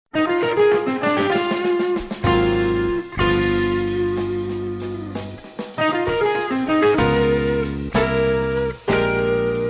TROMBA E FLICORNO
CHITARRA
PIANO
BASSO